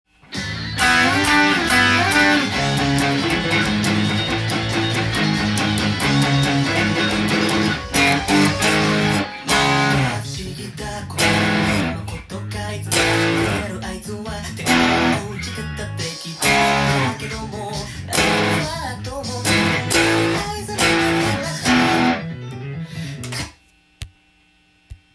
音源に合わせて譜面通り弾いてみました
誰でもすぐに弾けるようにパワーコードTAB譜にしてみました。
P.M＝ブリッジミュートで弾いていくので右手をブリッジ近くの弦を
この曲は、テンポが１３８となっていますので